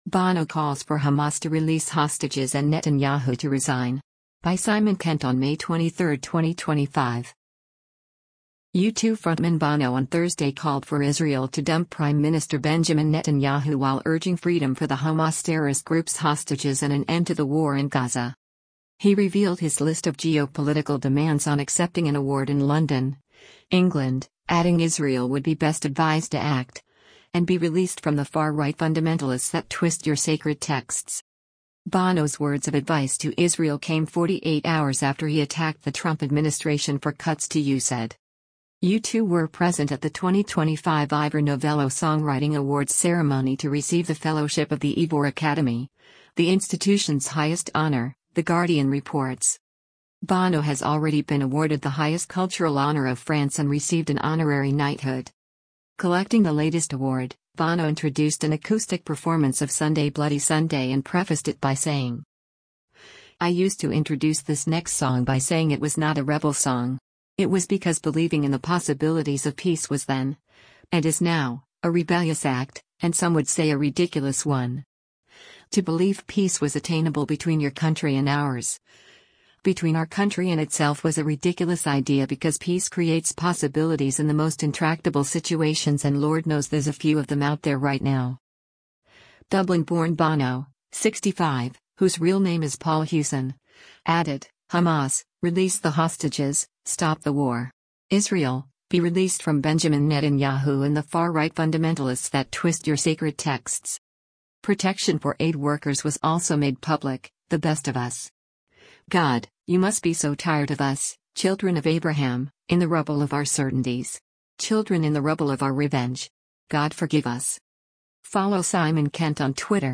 an acoustic performance